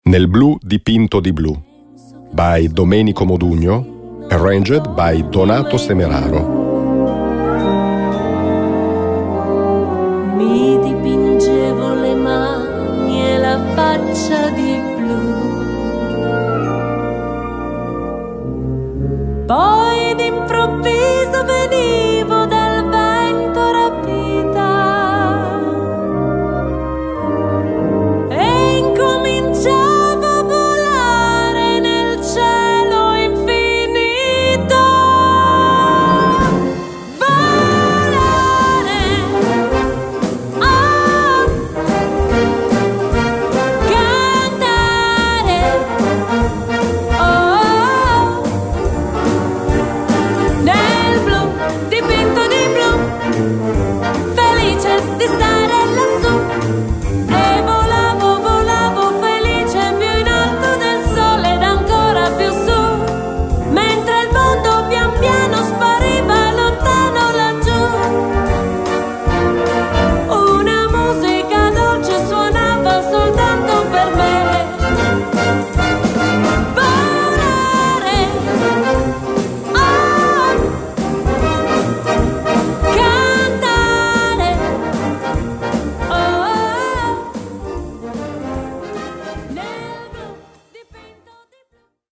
für Solo Gesang und Blasorchester